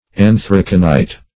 Search Result for " anthraconite" : The Collaborative International Dictionary of English v.0.48: Anthraconite \An*thrac"o*nite\, n. [See Anthracite .]
anthraconite.mp3